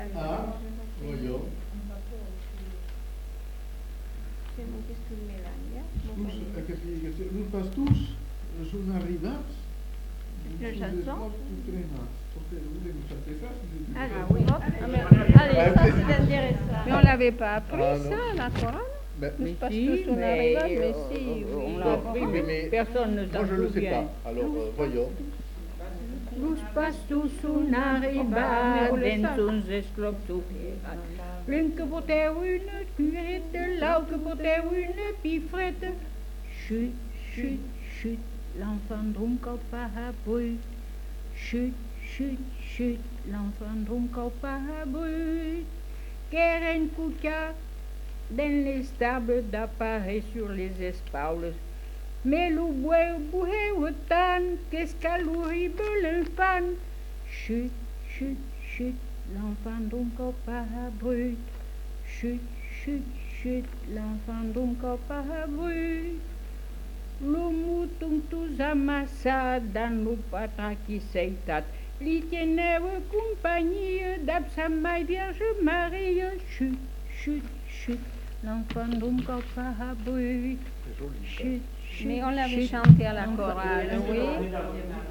Aire culturelle : Bazadais
Lieu : Bazas
Genre : chant
Effectif : 2
Type de voix : voix de femme
Production du son : chanté
Classification : noël